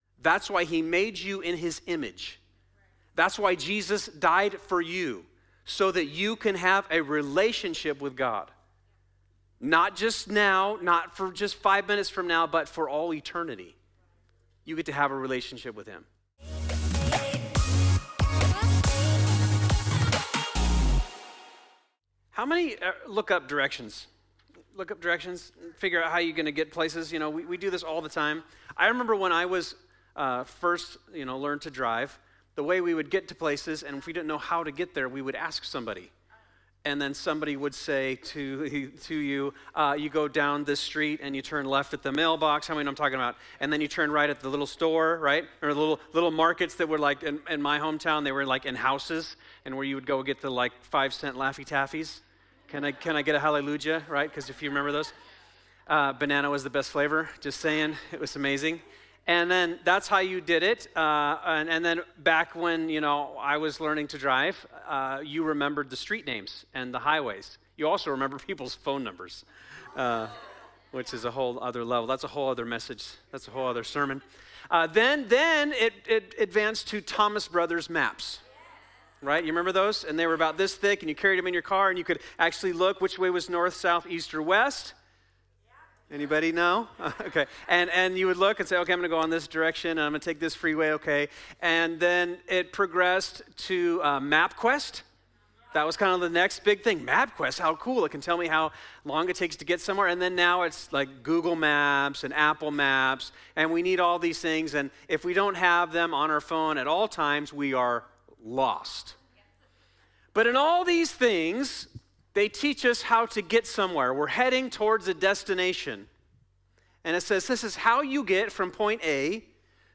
2025 The After Party Faith Salvation Works Wednesday Evening This is part 20 of "The After Party